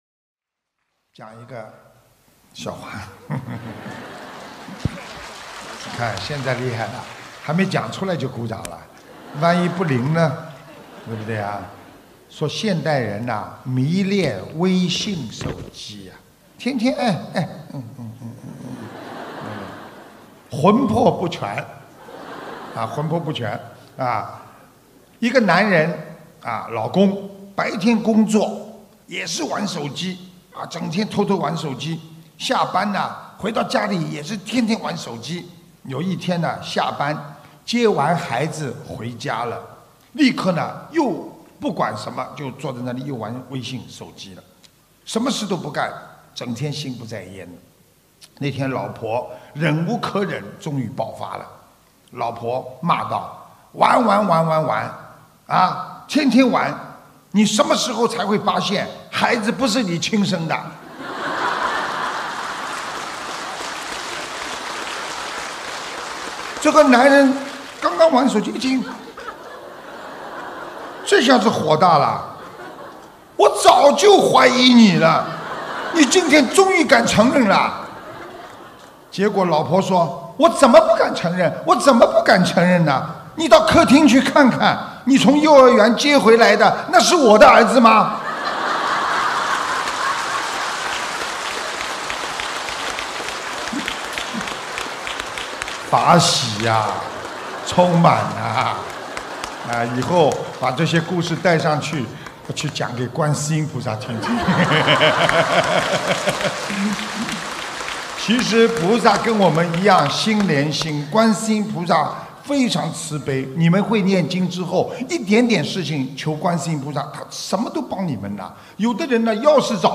音频：现代人迷恋微信，非常疯狂！·师父讲小故事大道理